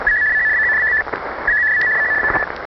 GMDSS_Marine_SELCAL_System.mp3